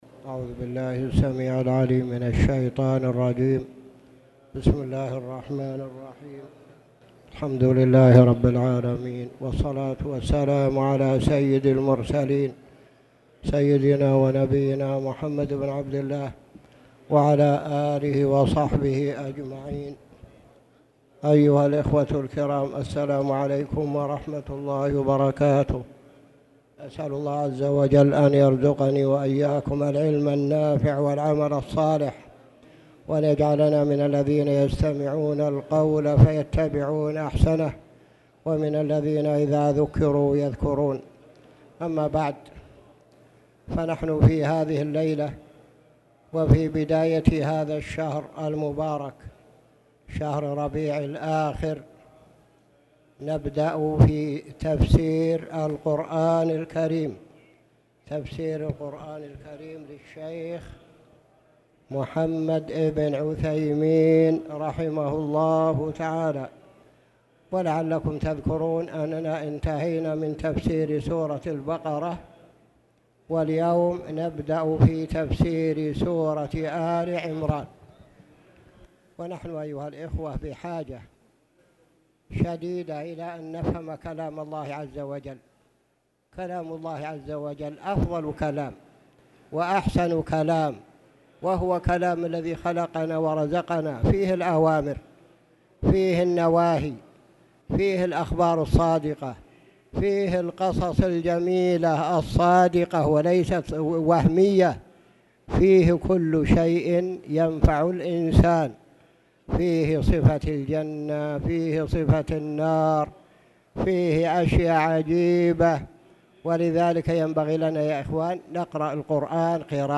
تاريخ النشر ٢ ربيع الثاني ١٤٣٨ هـ المكان: المسجد الحرام الشيخ